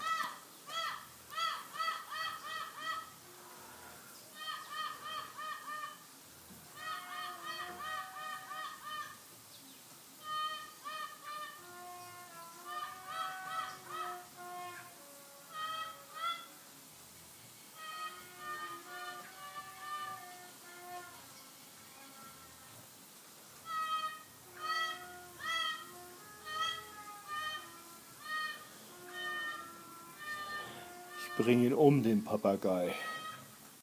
Sound am Sonntagnachmittag
So in etwa hört es sich bei uns an einem gewöhnlichen Sonntagnachmittag an.
Man beachte bitte den wahnsinnigen Papagei im Hintergrund und den noch wahnsinnigeren Trompetenspieler.